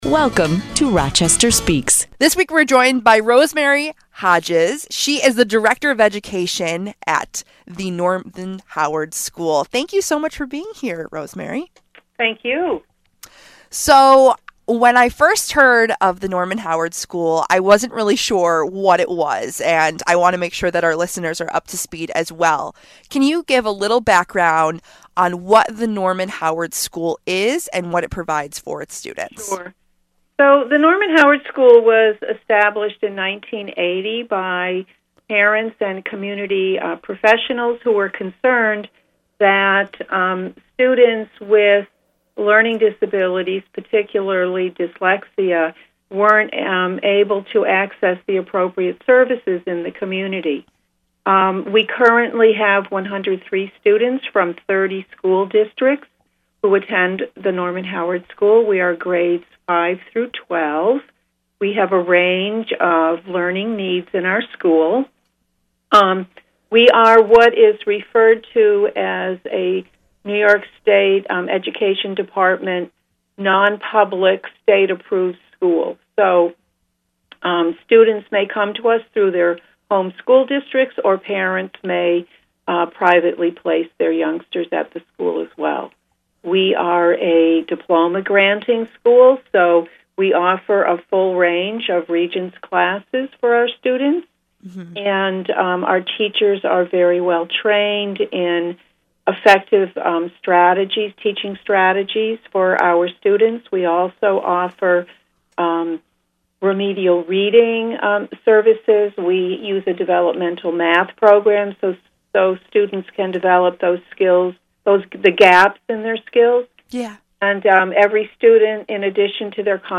Listen to their 15 minute interview HERE.